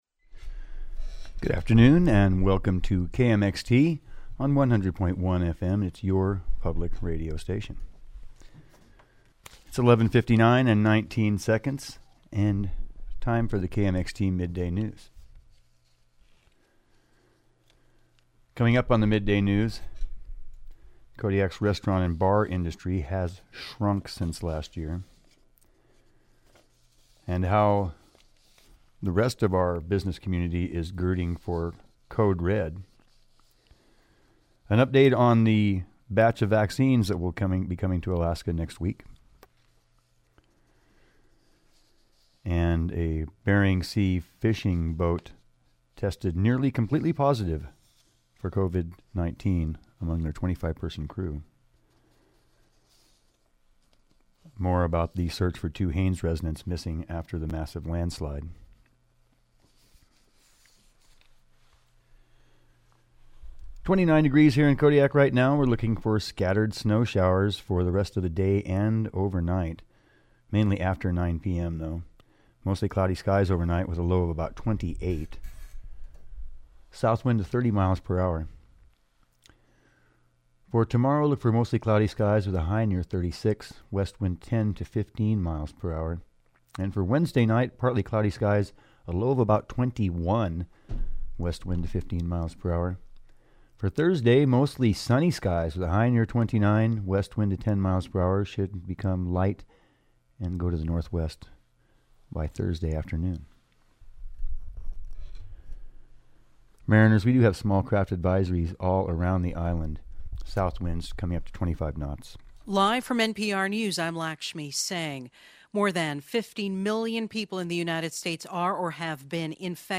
Newscast–Tuesday, December 8, 2020